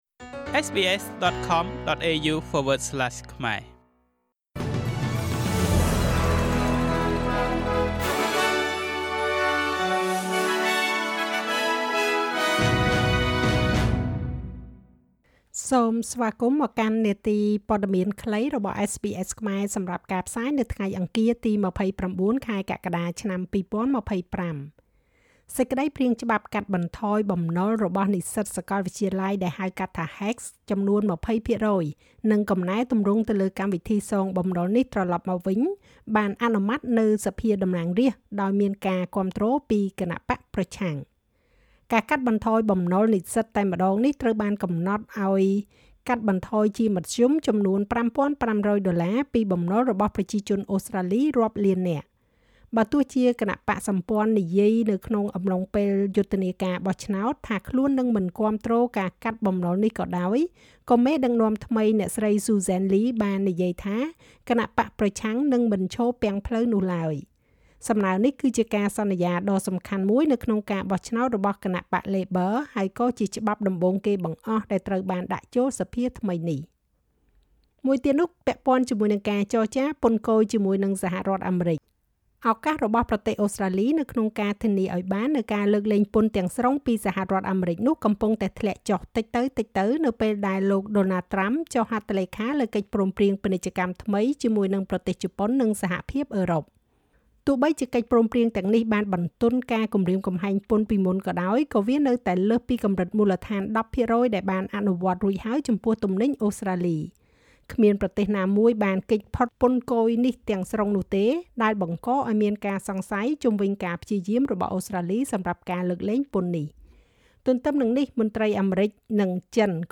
នាទីព័ត៌មានខ្លីរបស់SBSខ្មែរ សម្រាប់ថ្ងៃអង្គារ ទី២៩ ខែកក្កដា ឆ្នាំ២០២៥